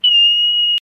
Play, download and share cat brain freeze AAAAAAAAAAAA original sound button!!!!